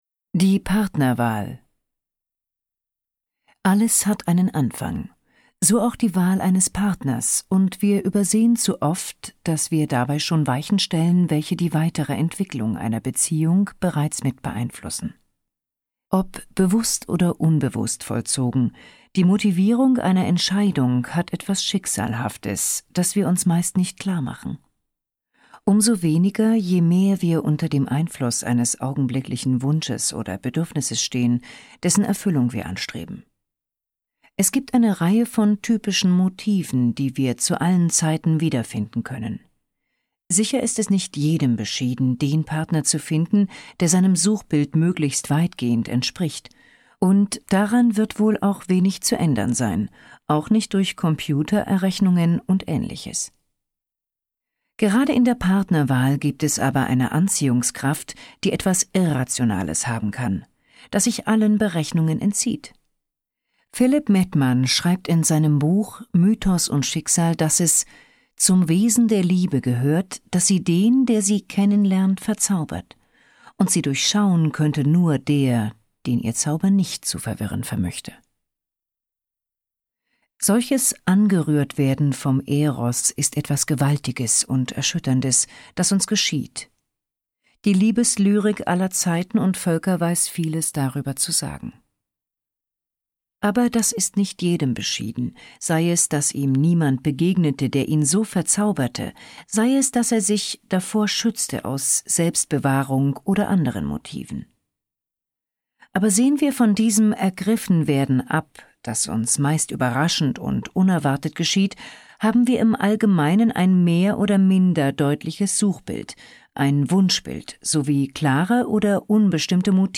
Die Fähigkeit zu lieben (Hörbuch)